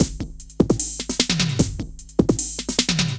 CRATE F DRUM 1.wav